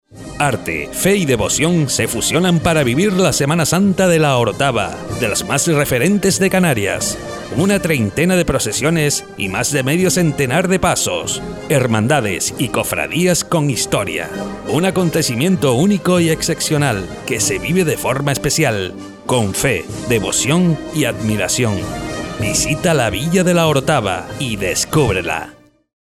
Cuña promocional